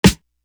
For The Record Snare.wav